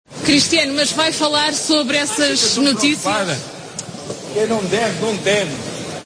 AUDIO: Respondió así a una periodista de la RTP tras el partido ante el Dortmund